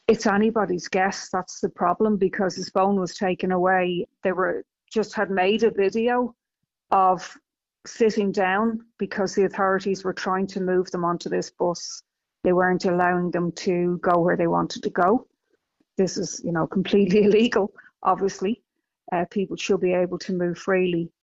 Solidarity People Before Profit TD Ruth Coppinger says it’s hard to know what’ll happen to Deputy Murphy next: